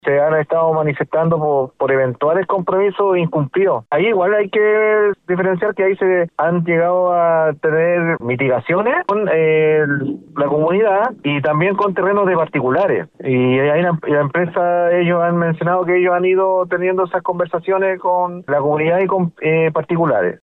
Por su parte, el alcalde de Olmué, Jorge Jil, indicó que han existido instancias de diálogo entre la empresa y los vecinos del sector de La Cuesta La Dormida.
cu-cuesta-la-dormida-aguas-pacifico-alcalde.mp3